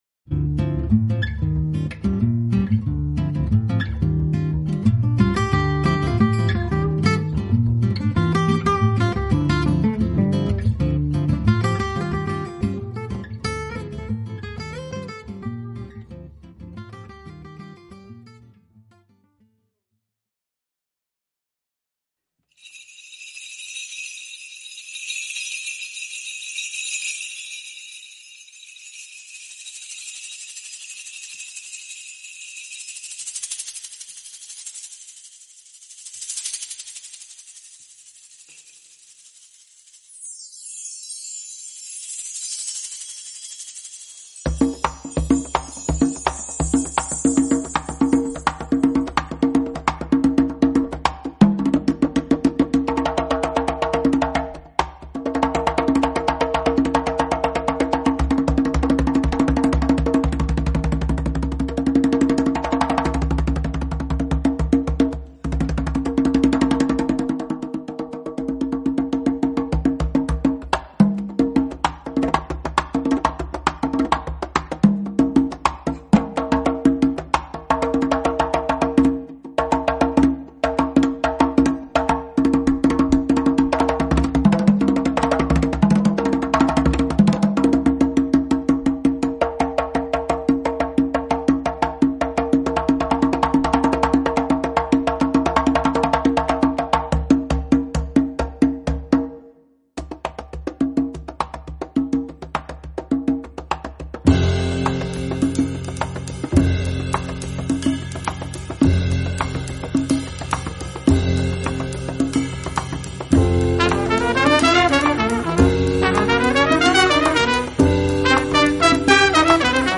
Japanese jazz artists